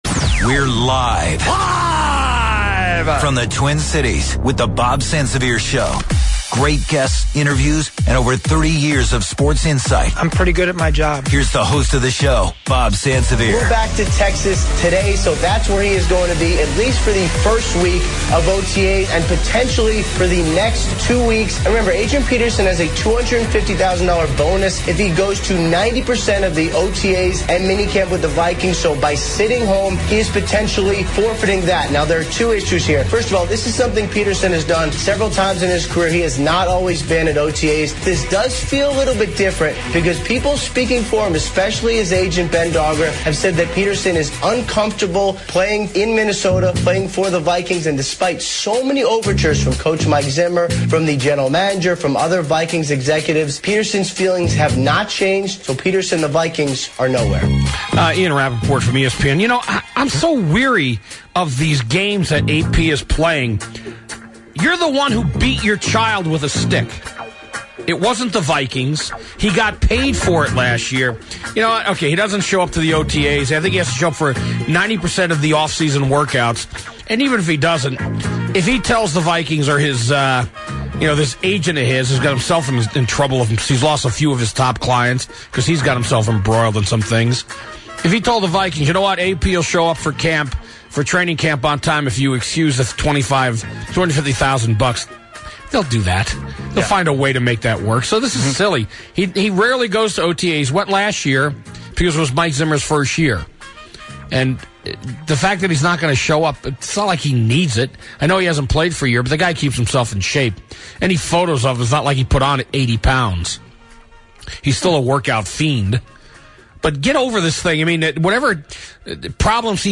Chuck Foreman calling in to talk the latest on Adrian Peterson.